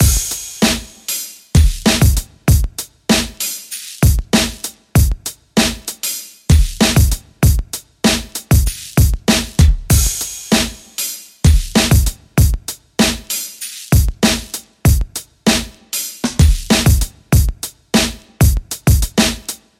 嘻哈大鼓3
Tag: 97 bpm Hip Hop Loops Drum Loops 3.33 MB wav Key : Unknown